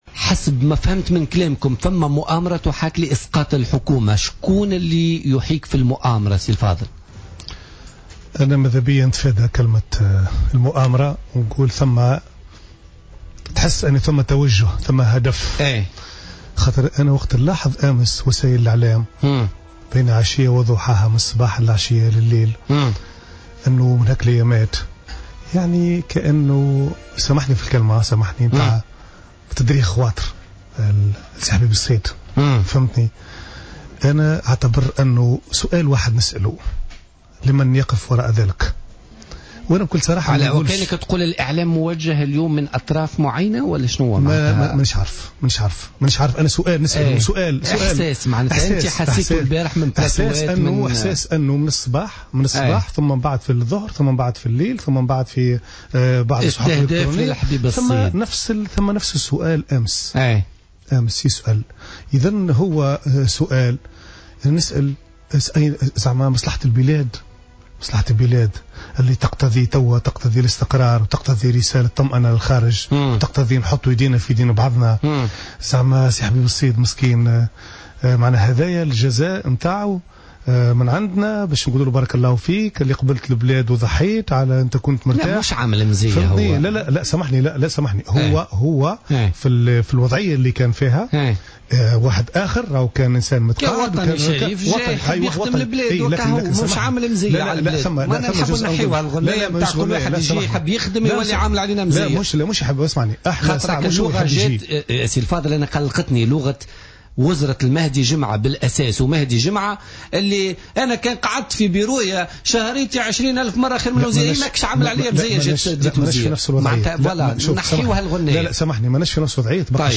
من جانب آخر اعتبر بن عمران، ضيف بوليتيكا اليوم الأربعاء، أن خروج بعض نواب الاتحاد الوطني الحر من كتلتهم والتحاقهم بكتلة نداء تونس، ليس دعما لحركة نداء تونس بقدر ما هو محاولة لزعزعة الاستقرار الحكومي وخلق أزمة حكم من خلال دفع الاتحاد الوطني الحر إلى الانسحاب من التحالف الحاكم، وفق تعبيره.